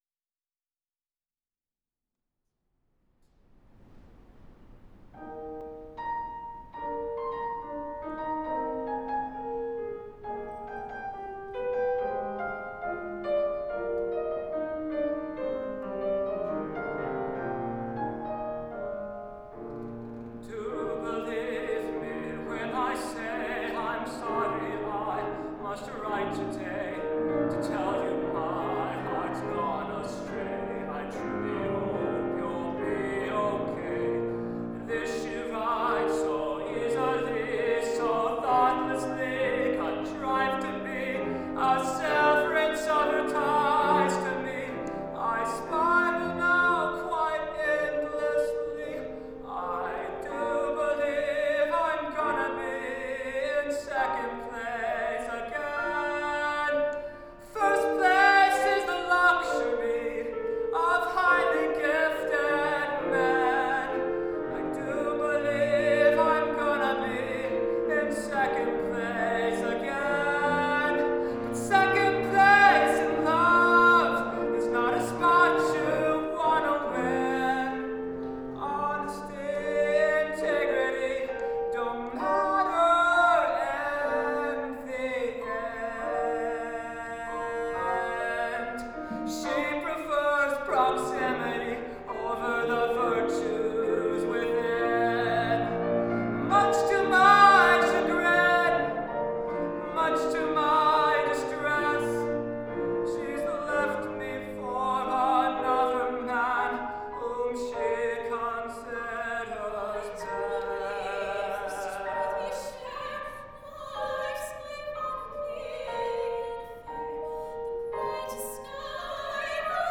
Performance of Select Movement from the Vocal Score:
soprano
baritone
piano